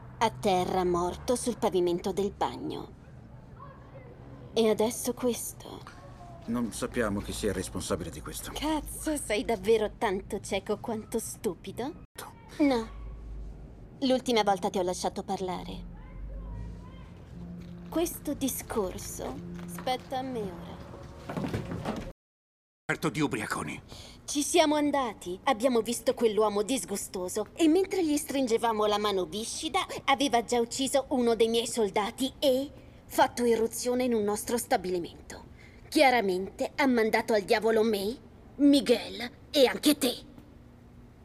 Il mondo dei doppiatori
VIDEOGAMES, MULTIMEDIA, DOCUMENTARI  (per eventuali attività, consultate le schede presenti nella sezione EXTRA di questo sito)